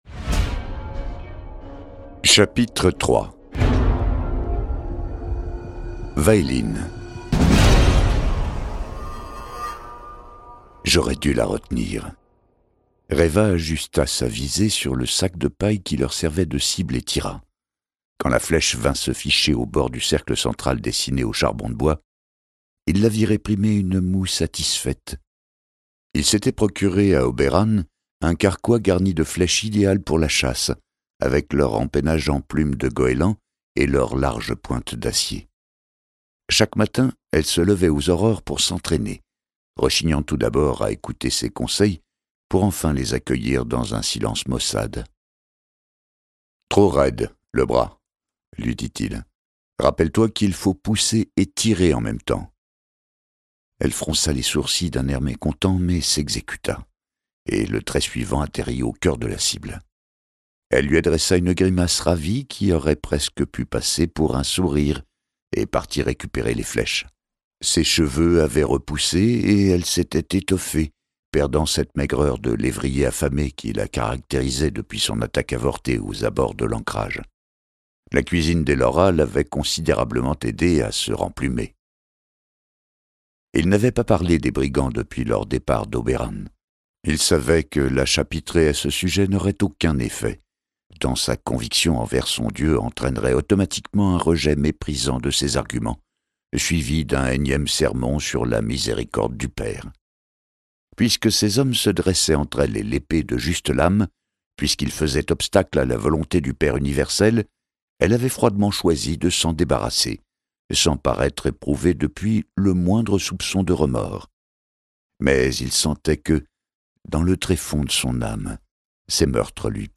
» - Buzzfeed Ce livre audio est interprété par une voix humaine, dans le respect des engagements d'Hardigan.